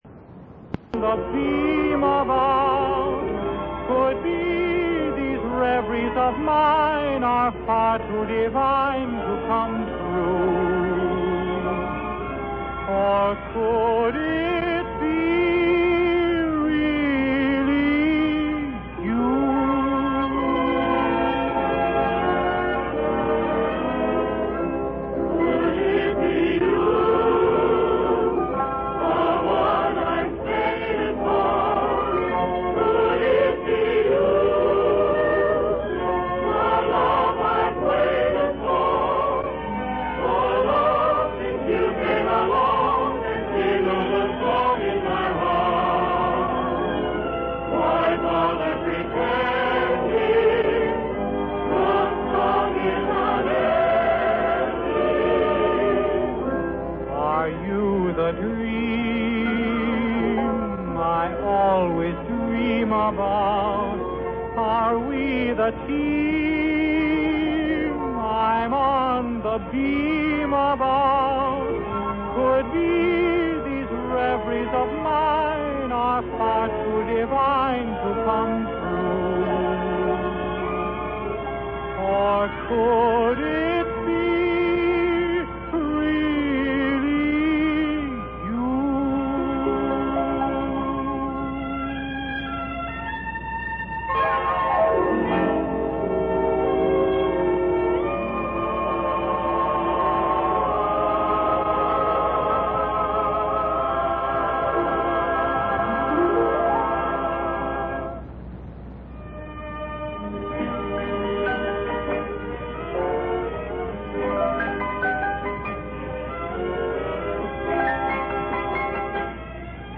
Music Clip   4:42 Unknown music show